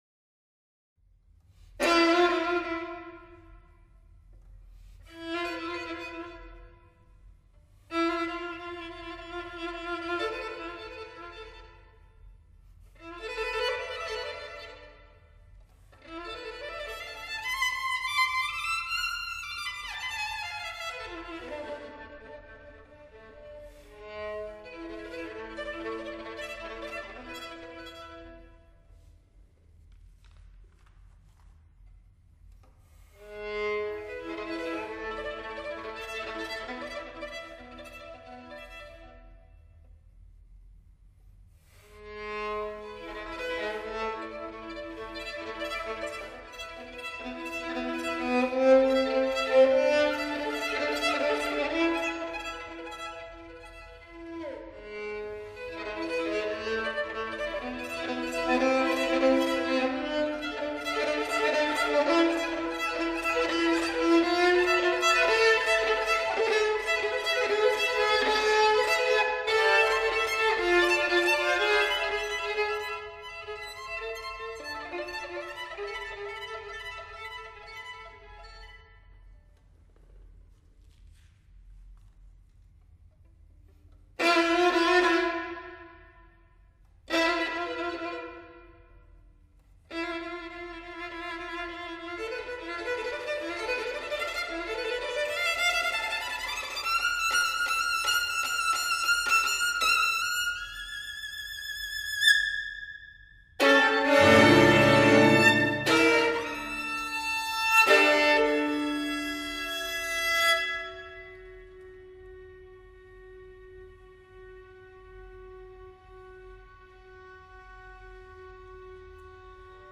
for String Octet